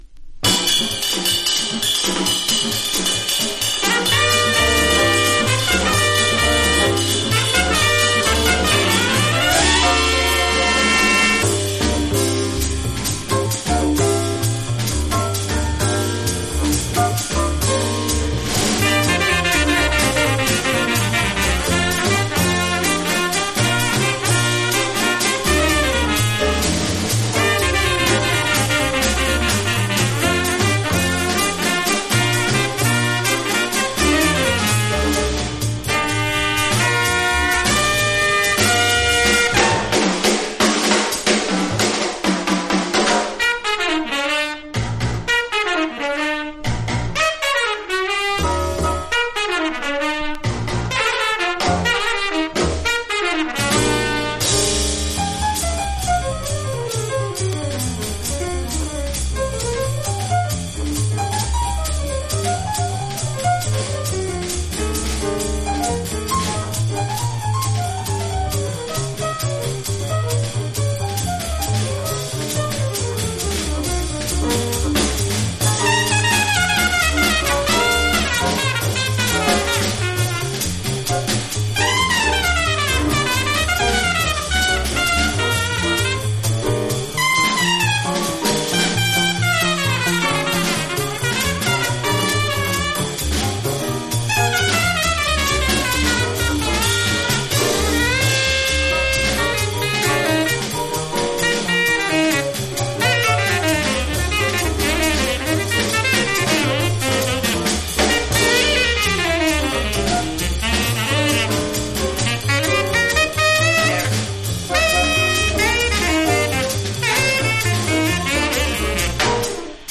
（プレス・小傷によりチリ、プチ音ある曲あり）
Genre JAPANESE JAZZ